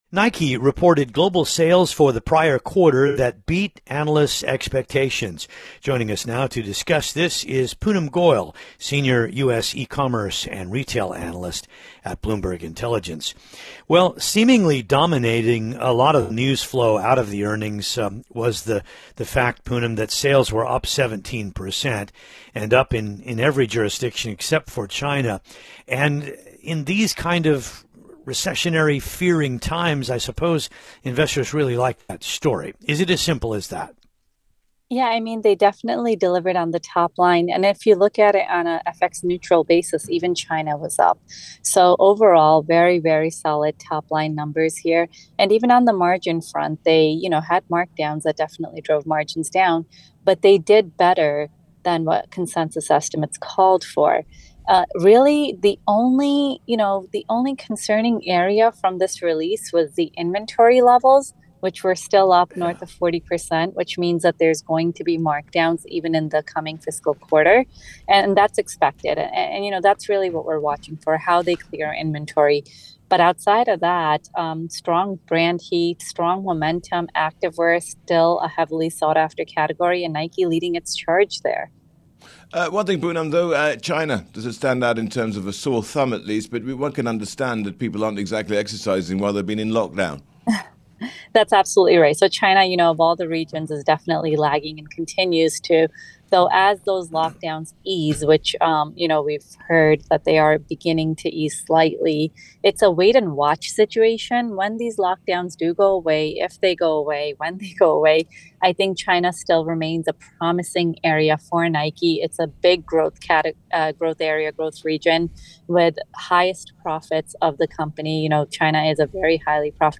(Radio)